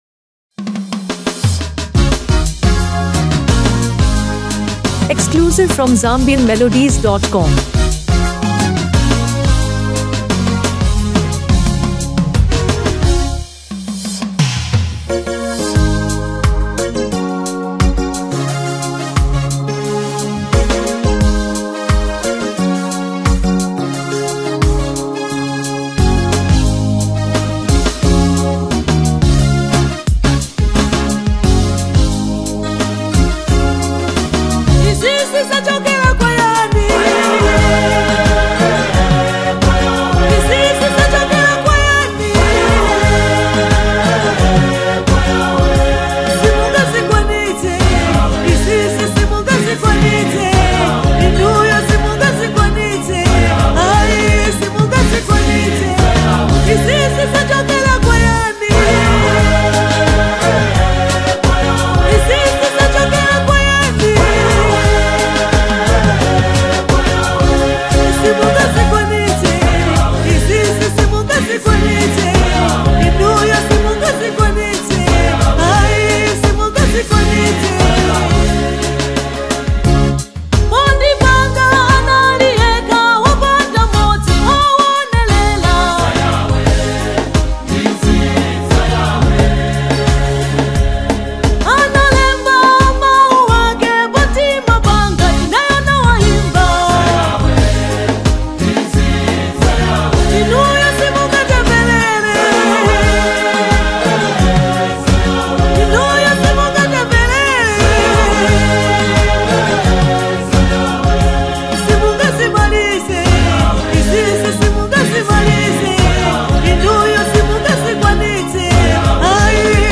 A Spirit-Lifting Gospel Anthem